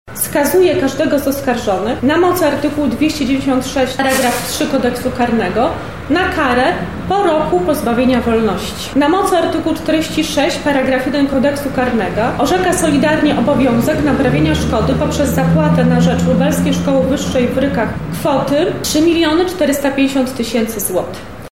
Wyrok ogłosiła sędzia Aleksandra Machel-Dzik.